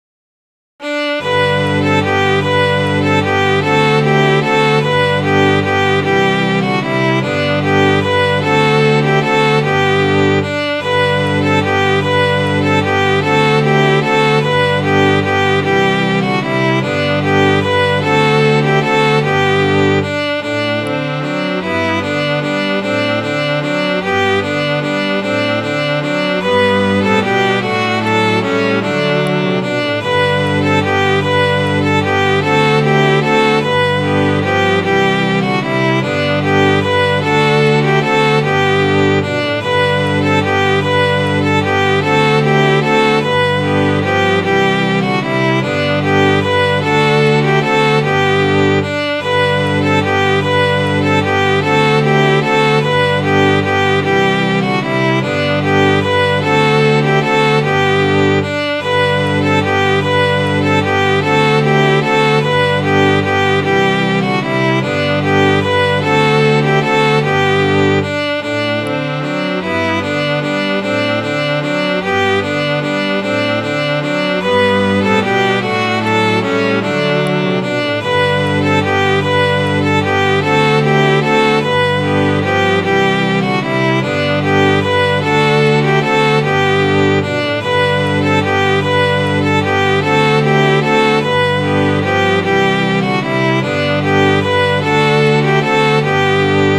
Midi File, Lyrics and Information to The Old Oaken Bucket